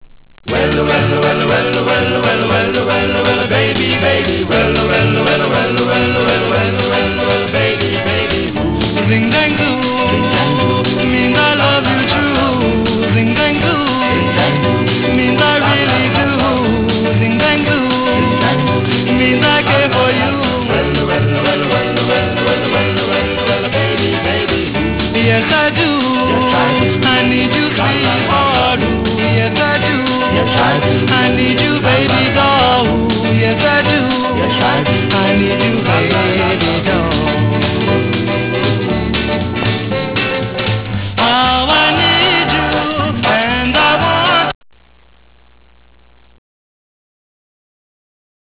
Garage Rock WAV Files